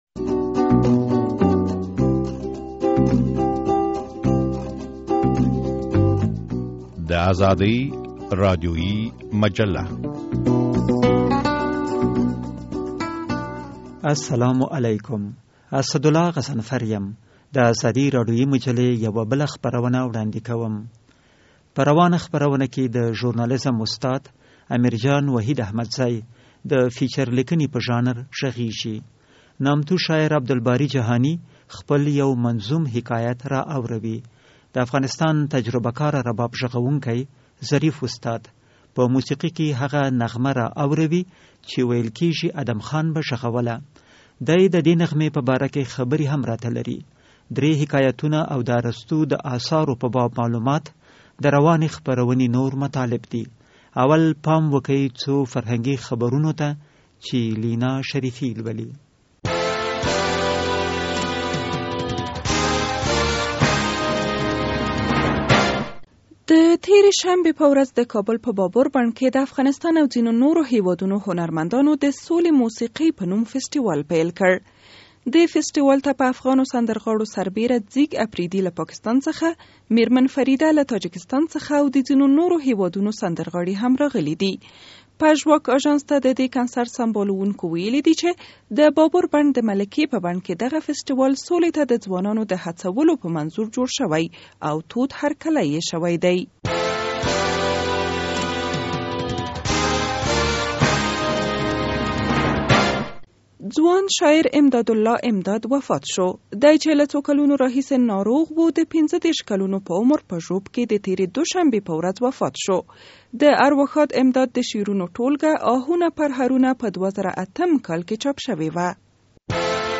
تجربه کار رباب غږوونکی